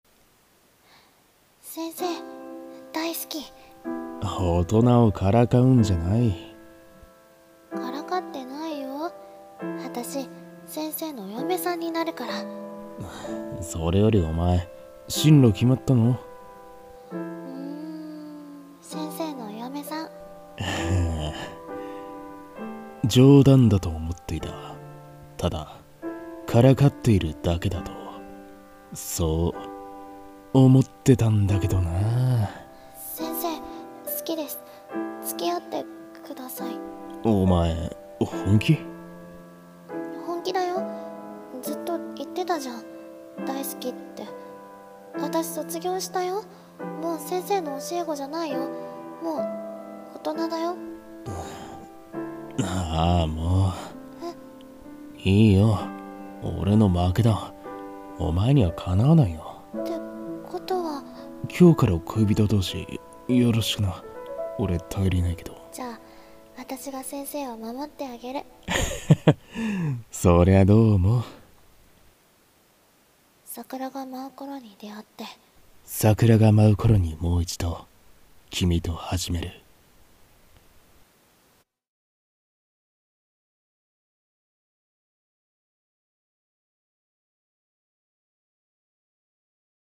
【声劇】桜が舞う頃に、君と。【2人声劇】 演